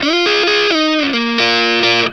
BLUESY3 BF90.wav